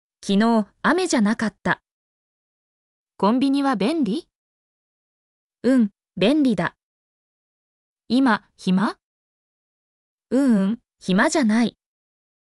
mp3-output-ttsfreedotcom-74_fliWspZY.mp3